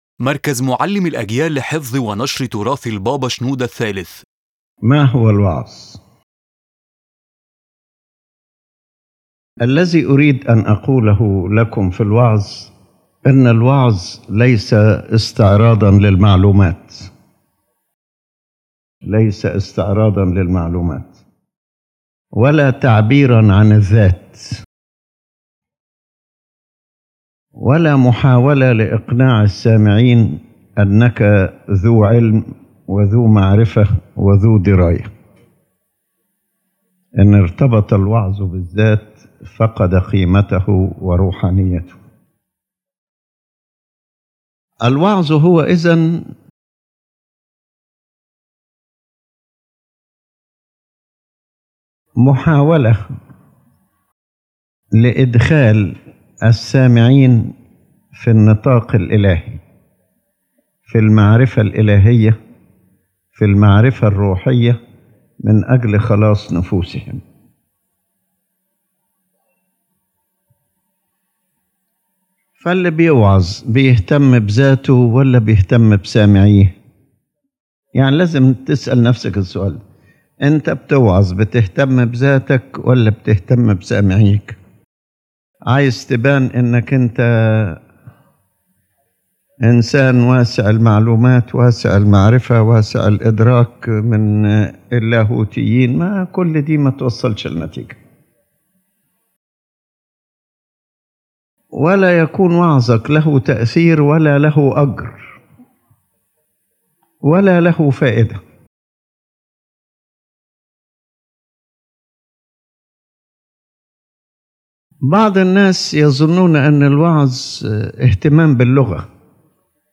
Preaching (Sermon) — Part 1
His Holiness Pope Shenouda III defines in this lecture the essence of true preaching: preaching is not a display of information nor the glorification of the self, but a spiritual means aimed at bringing listeners into the divine sphere of knowing God and the salvation of souls.